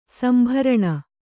♪ sambharana